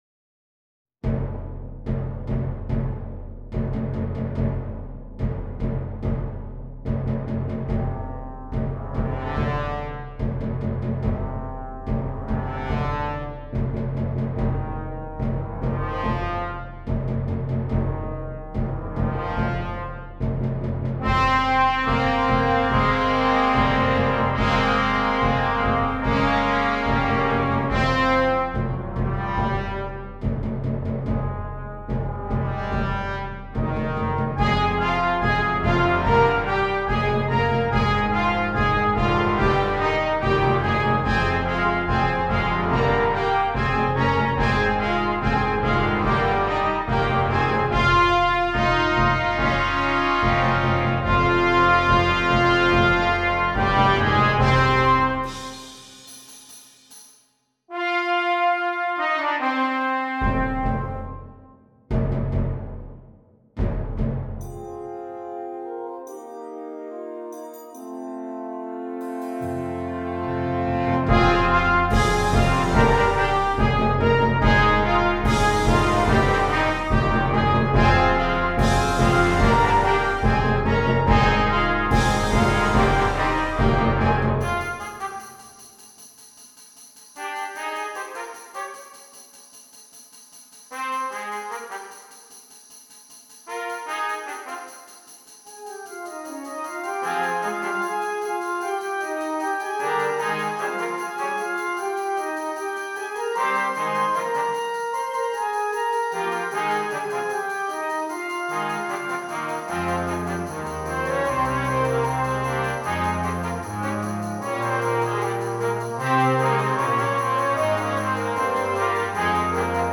Voicing: Brass Choir